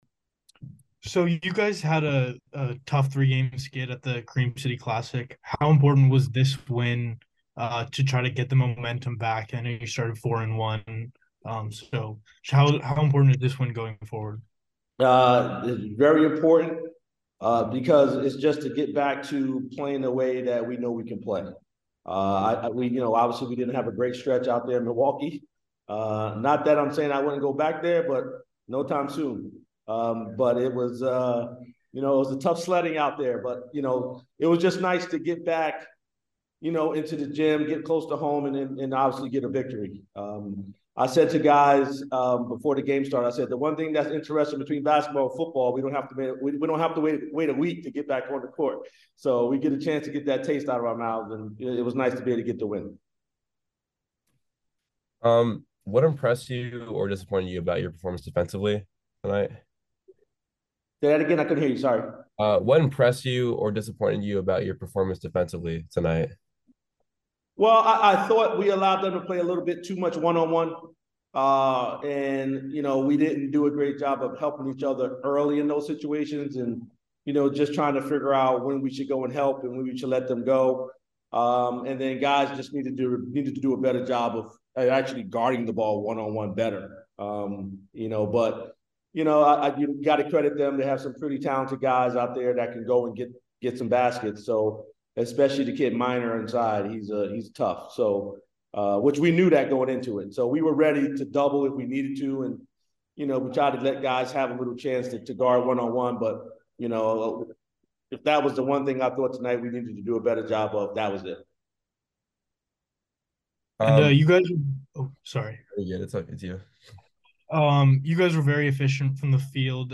Merrimack Postgame Interview